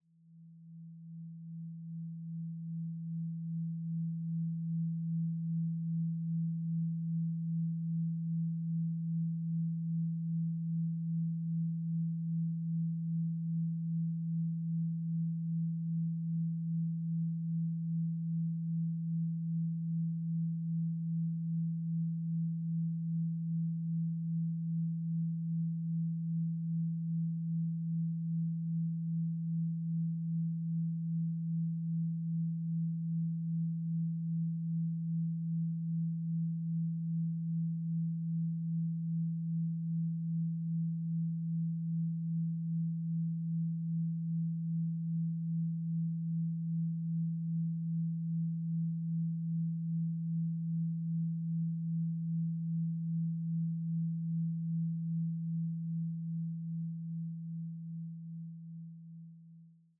Duration: 1:00 · Genre: Chillhop · 128kbps MP3